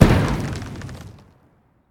poison-capsule-explosion-1.ogg